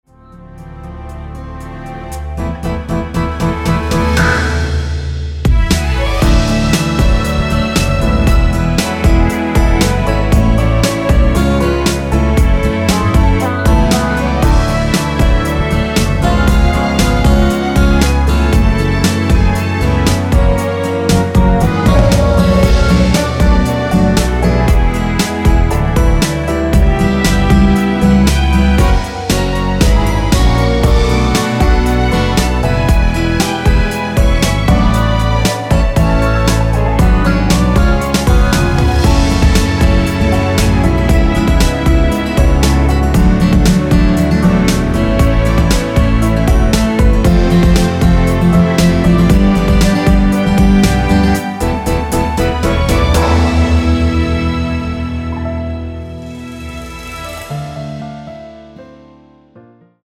원키에서(-3)내린 (1절+후렴)으로 진행되는 MR입니다.
Gbm
앞부분30초, 뒷부분30초씩 편집해서 올려 드리고 있습니다.
중간에 음이 끈어지고 다시 나오는 이유는